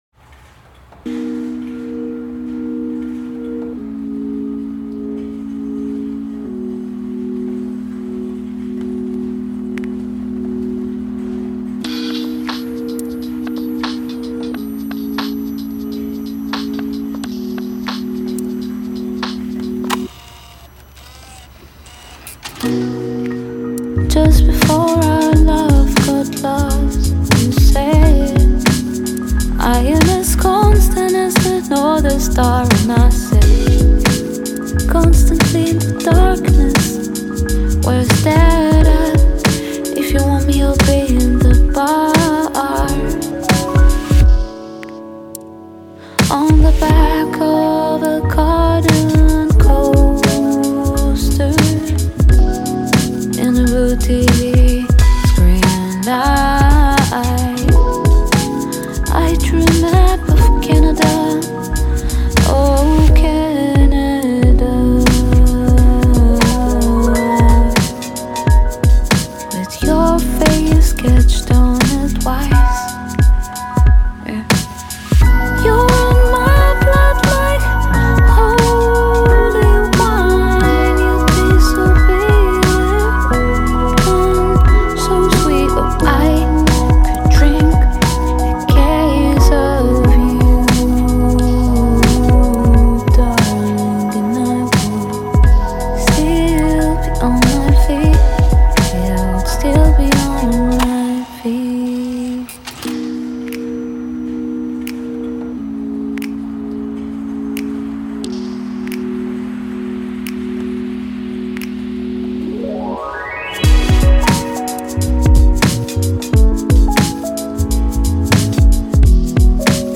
> JAPANESE (ポップ/ソウル/ファンク/レゲエ/和モノ) NEW・RE-STOCK
REMIX